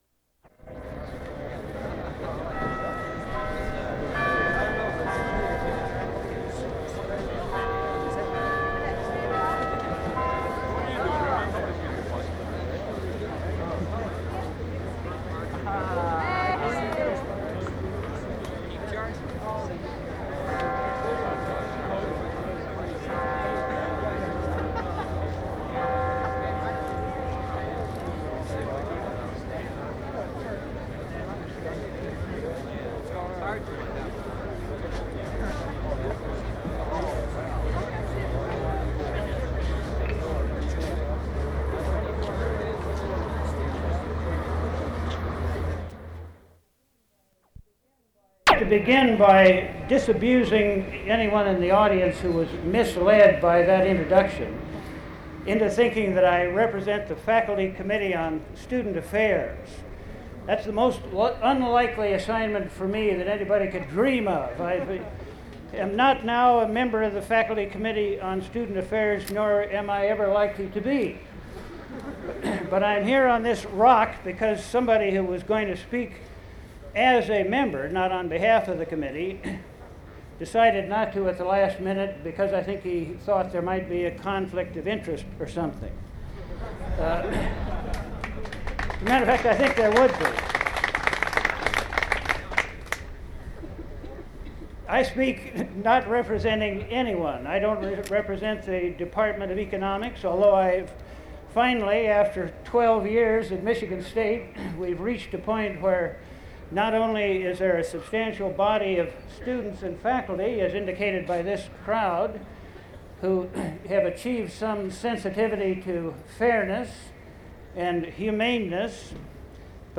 Various student groups, including the Student Liberation Alliance (SLA), hold a September 30, 1968 rally at Beaumont Tower. The groups were opposed to the September 20 decision by President Hannah and members of the Board of Trustees to uphold a student suspension ruling.